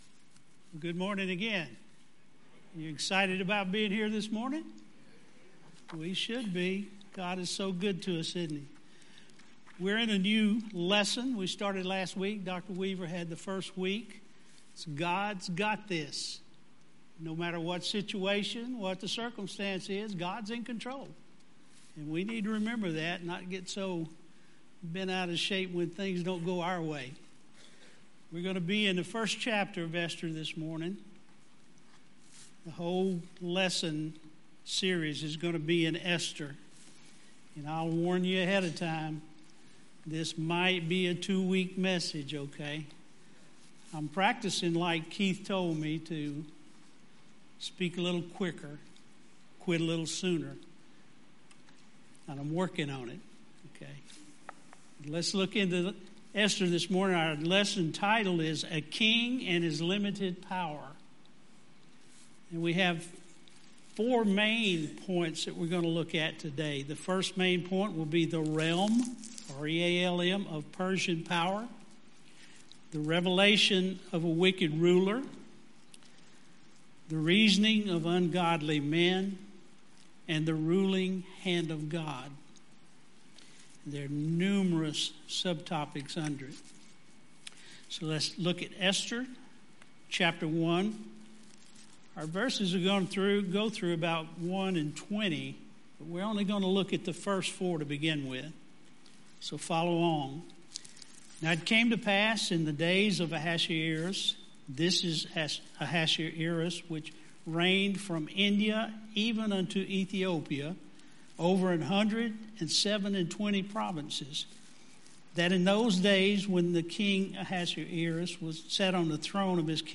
Sermon Audio A King and His Power